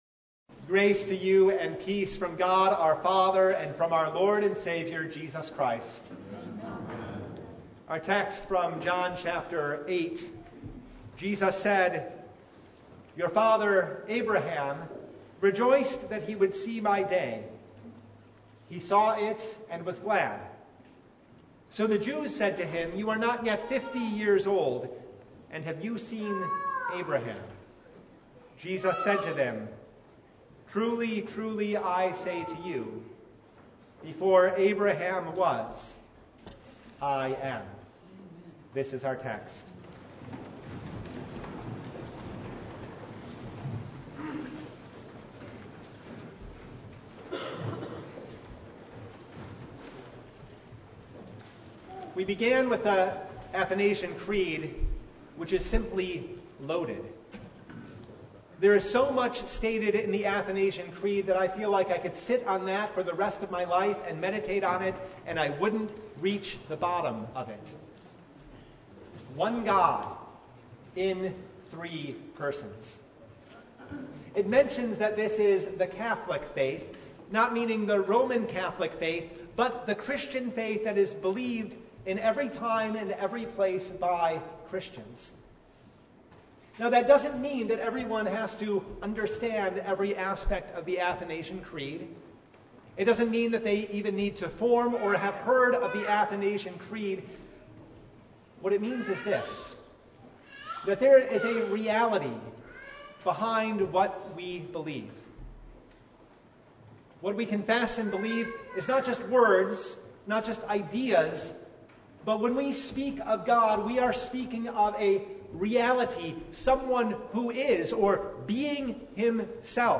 Service Type: The Feast of the Holy Trinity